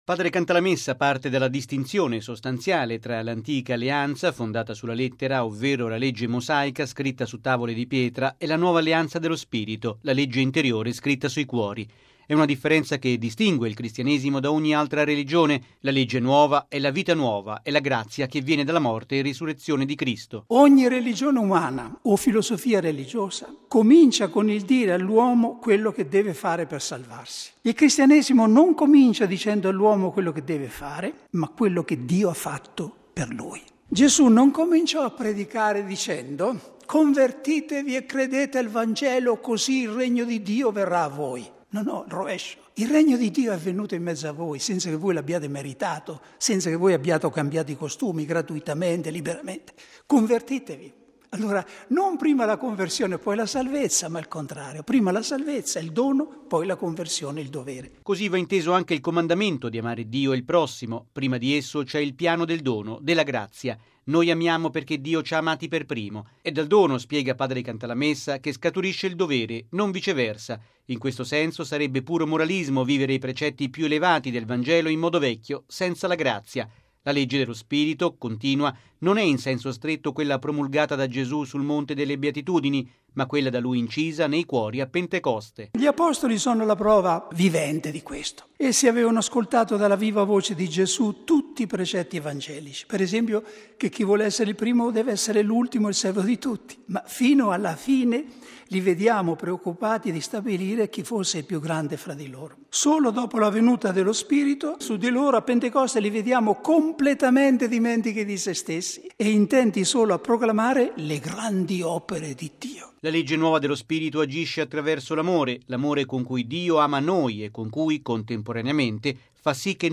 ◊   Si è svolta stamane nella Cappella Redemptoris Mater del Palazzo Apostolico, in Vaticano, la prima predica di Quaresima di padre Raniero Cantalamessa davanti al Papa e alla Curia Romana.